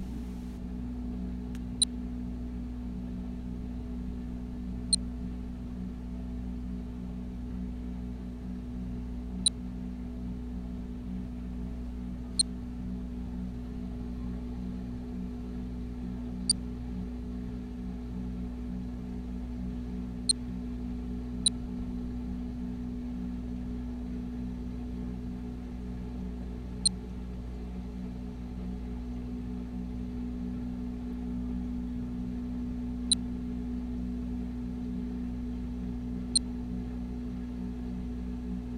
ambiance_Demeter.ogg